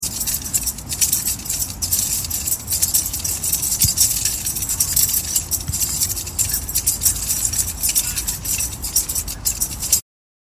ｼﾞｬﾗﾗﾗﾘﾘｼﾞｬﾗﾗﾗﾘﾘｼﾞｬﾗﾗﾗﾘﾘｼﾞｬﾗﾗﾗﾘﾘｼﾞｬﾗﾗﾗﾘﾘｼﾞｬﾗﾗﾗﾘﾘｼﾞｬﾗﾗﾗﾘﾘｼﾞｬﾗﾗﾗﾘﾘ